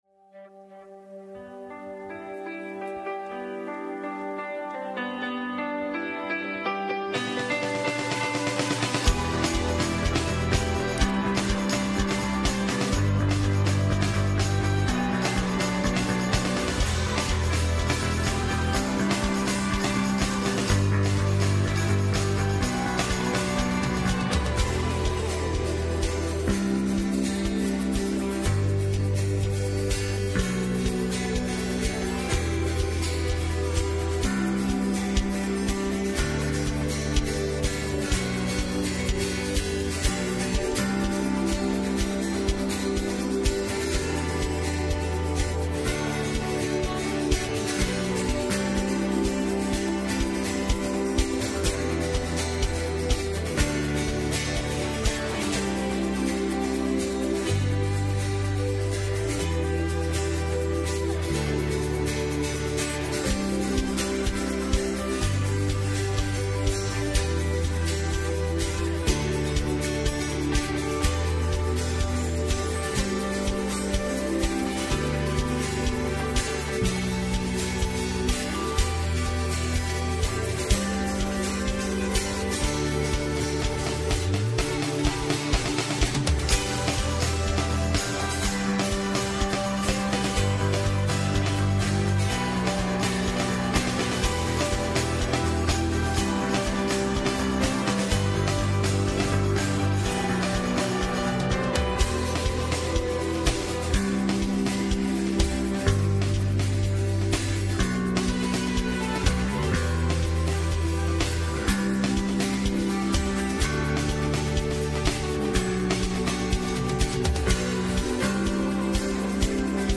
Westgate Chapel Sermons